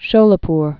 (shōlə-pr)